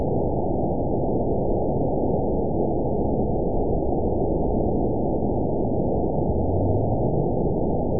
event 922687 date 03/10/25 time 07:15:39 GMT (3 months, 1 week ago) score 8.54 location TSS-AB10 detected by nrw target species NRW annotations +NRW Spectrogram: Frequency (kHz) vs. Time (s) audio not available .wav